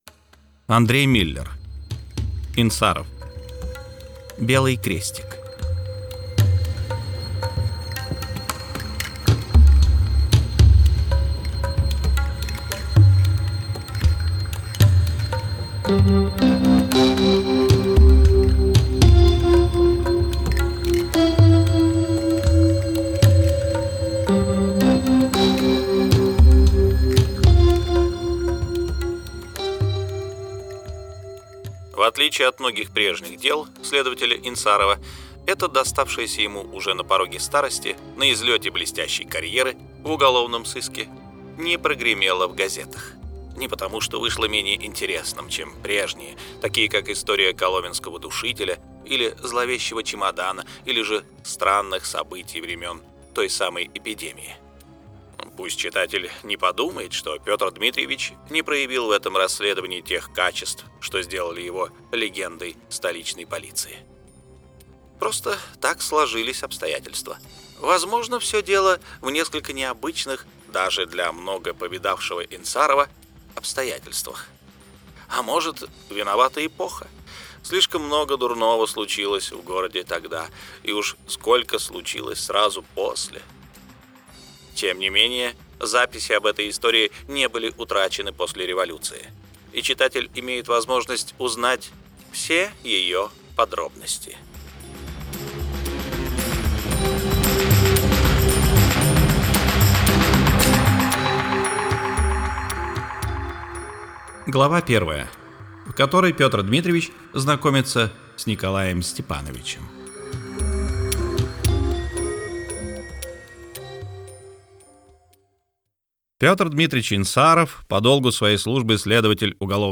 Аудиокнига Инсаров 3. Белый крестик | Библиотека аудиокниг